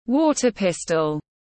Súng nước tiếng anh gọi là water pistol, phiên âm tiếng anh đọc là /ˈwɔː.tə ˌpɪs.təl/
Water pistol /ˈwɔː.tə ˌpɪs.təl/
Water-pistol-.mp3